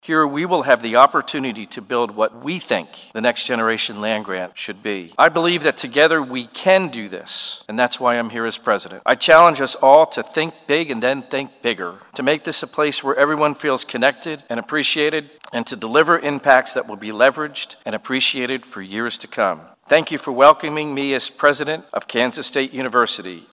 President Richard Linton officially took on the title following a ceremony held in McCain Auditorium.
Kansas Governor Laura Kelly spoke highly of the new president and his goals for the future.